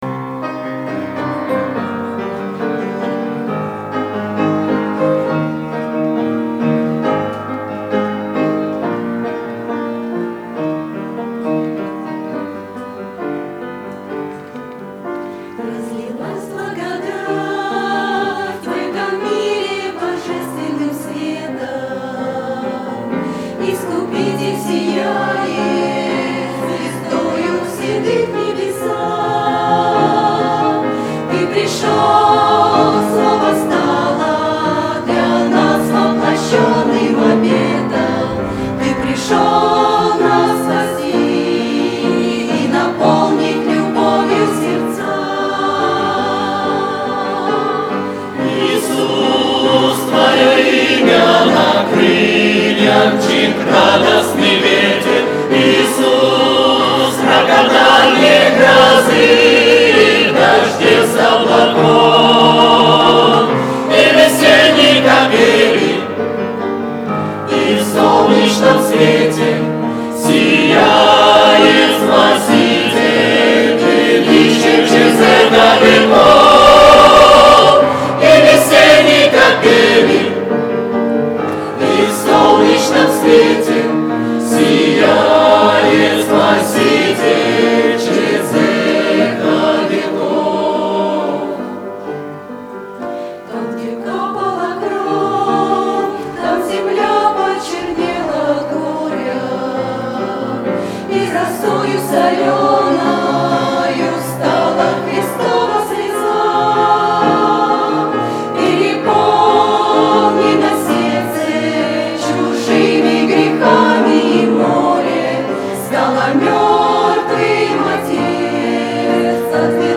Хор г. Павлодар on 2014-06-21 - Фестиваль христианской музыки и песни